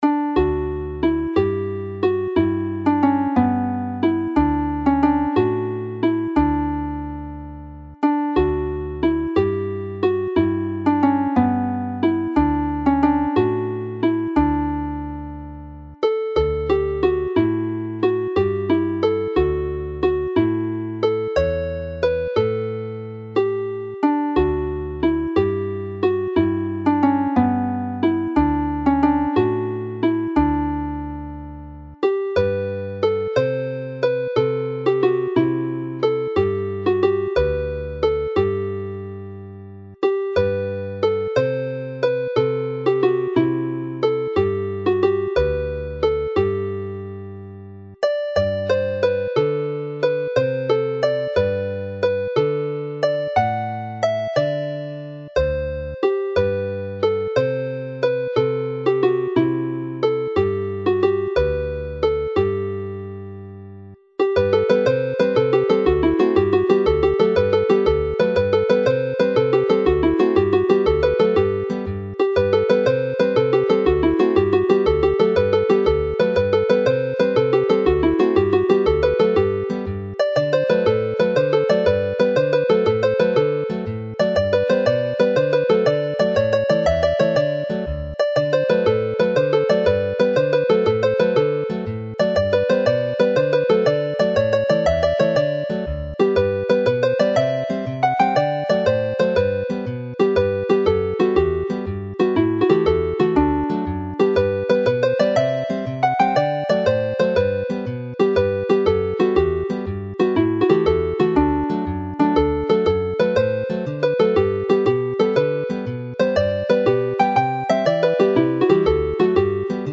This Christmas set is based on an old Plygain Carol, Ar Dymor Gaeaf (in the winter season).
The jig version in G illustrates how a tune can be interpreted in many ways and runs nicely into Ffanni Blodau'r Ffair which has already appeared in the dance set for Cylch y Cymry in this collection.